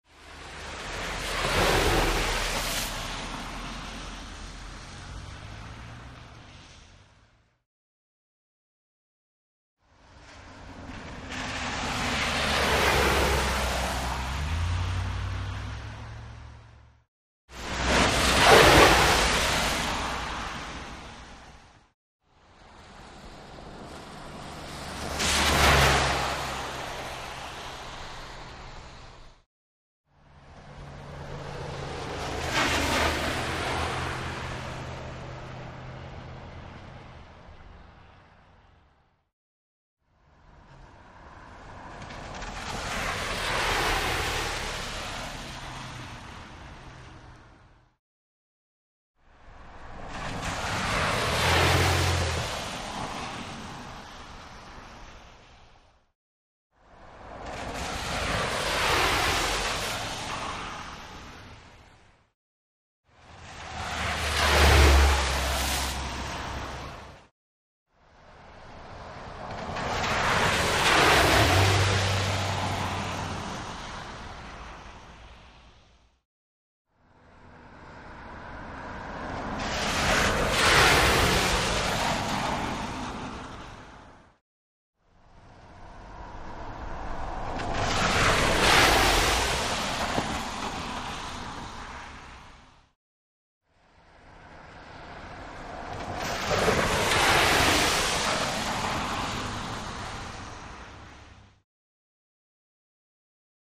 Car Tires Wet; Auto Bys Through Flooded Street, Big Sprays Various